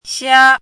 怎么读
xiā